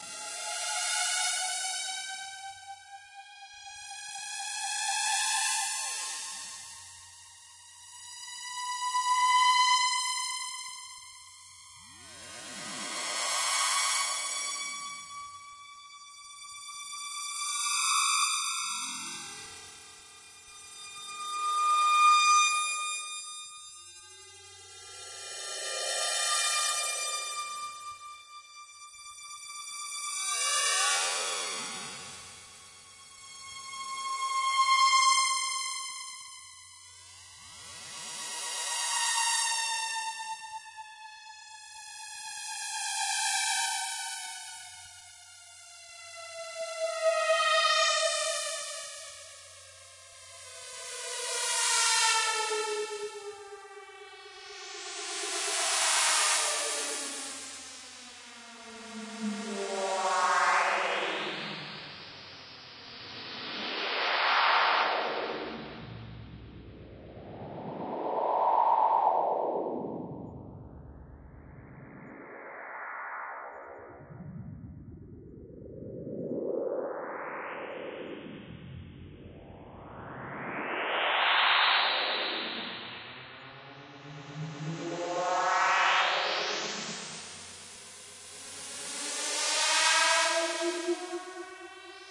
Tag: 机械 电子 机械 未来 航天器 外星人 空间 科幻 噪音